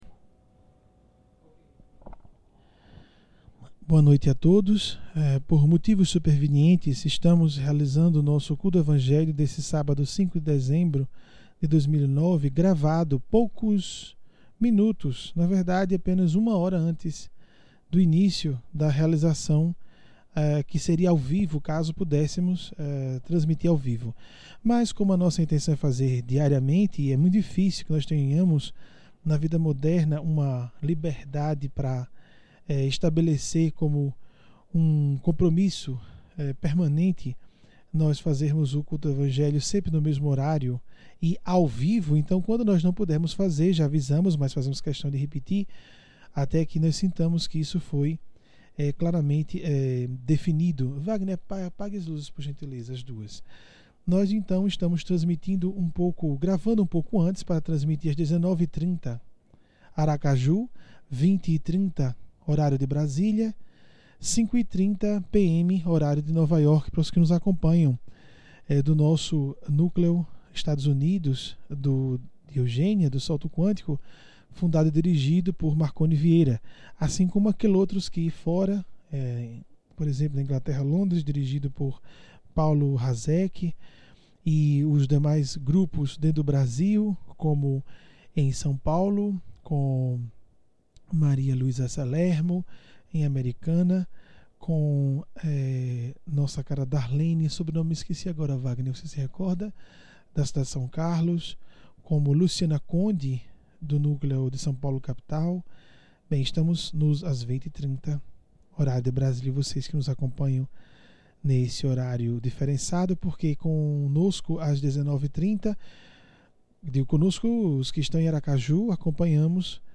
Culto do Evangelho
Palestras memoráveis e práticas do Evangelho